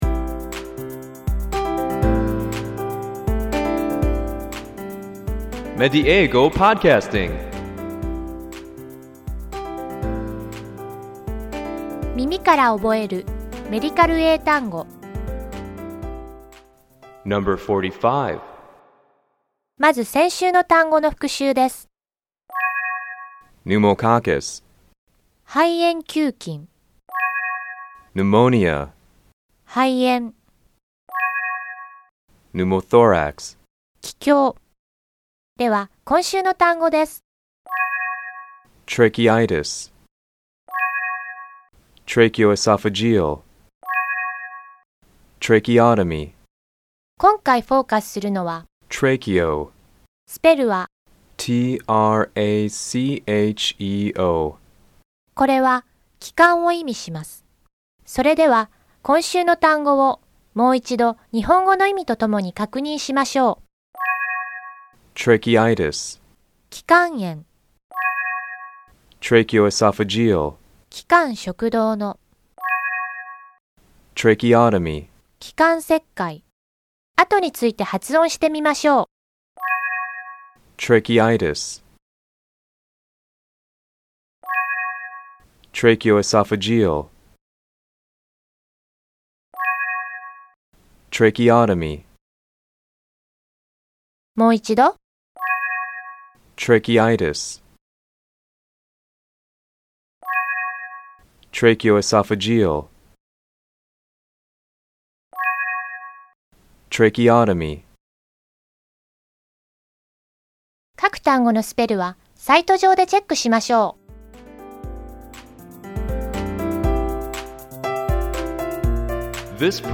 ネイティブの発音を聞いて，何度も声に出して覚えましょう。